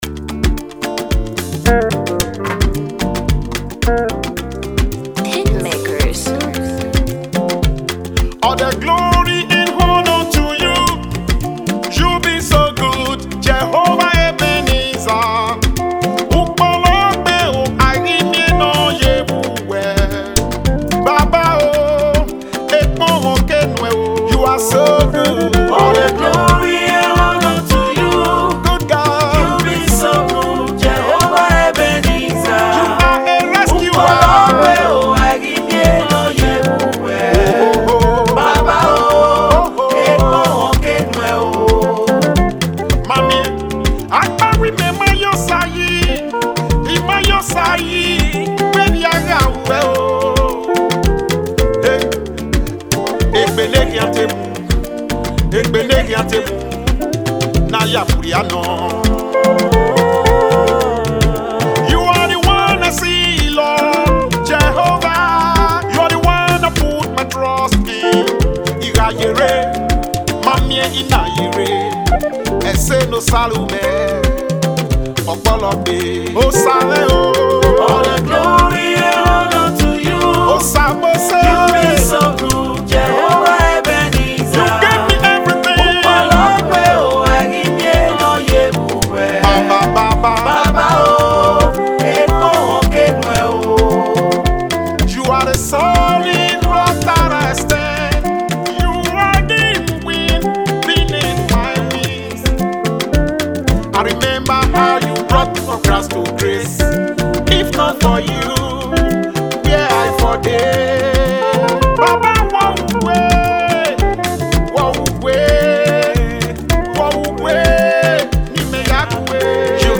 Multiple award winning international Christian music artist